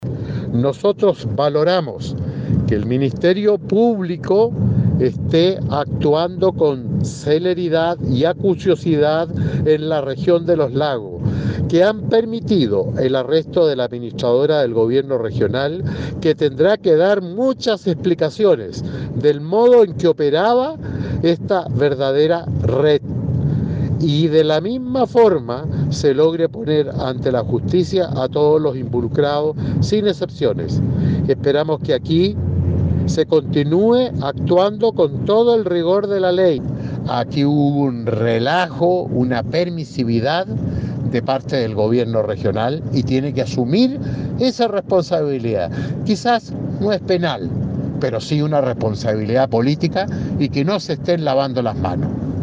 La diligencia concretada al mediodía de ayer martes tuvo repercusiones en los más diversos ámbitos, por ello el senador Iván Moreira valoró la acción del ministerio Público.